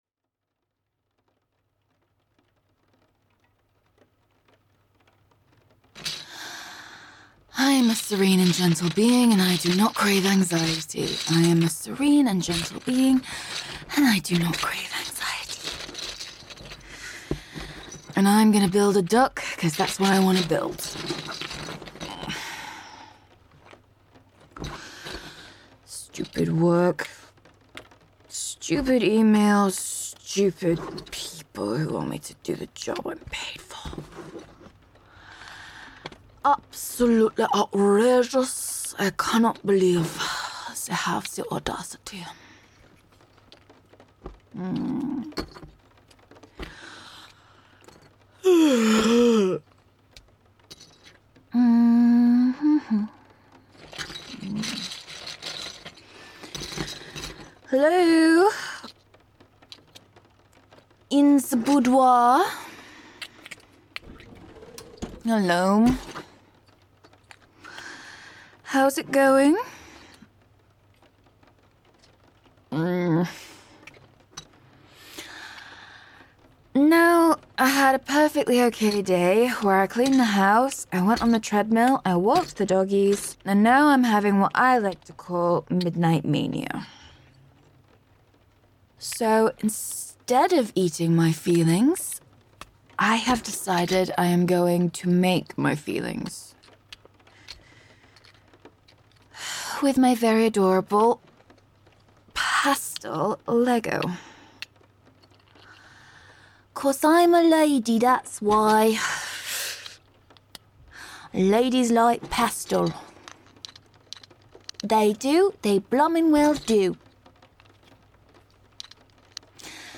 (Time to Unwind with Lego in Bed) (Soft Rambling & Building) (Gentle & Relaxed)